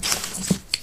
chipsHandle5.ogg